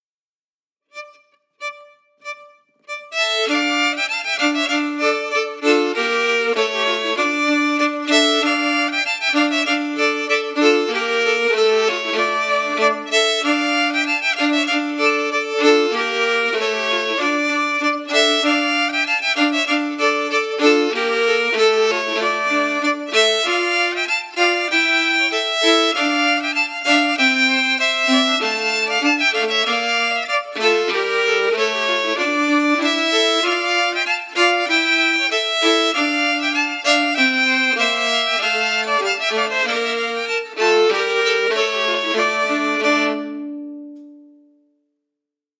Key: D-minor
Form: Scottish Reel Song
MP3: Harmony emphasis